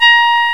GS1HORN.WAV